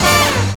JAZZ STAB 5.wav